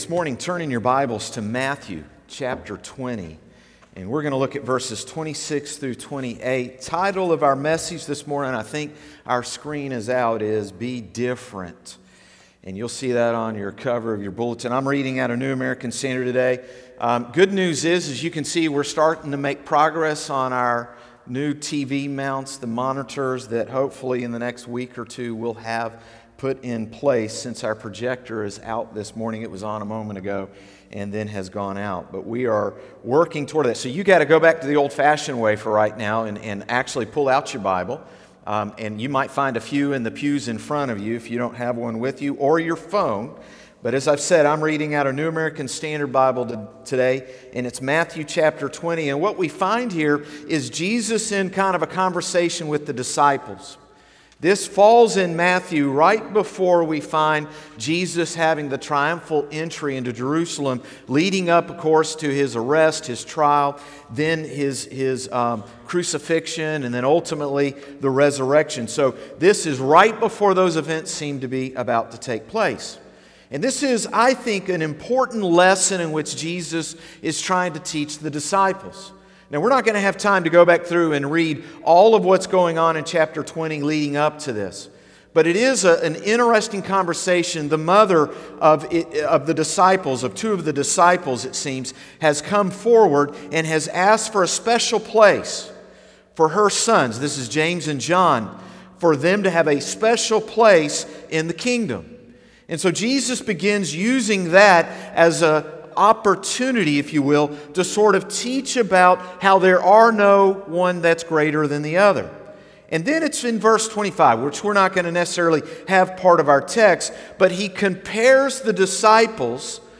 Sermons - Concord Baptist Church
Morning-Service-9-06-20.mp3